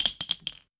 weapon_ammo_drop_14.wav